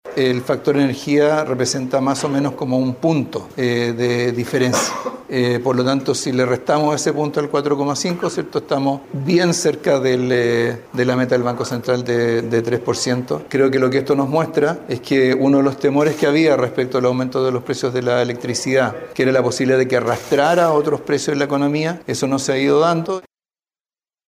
En un seminario de ICARE, el ministro de Hacienda, Mario Marcel, se refirió a la última alza que está programada para este mes, asegurando que si se resta este componen de la cifra anual, estamos más cerca de la meta del Central.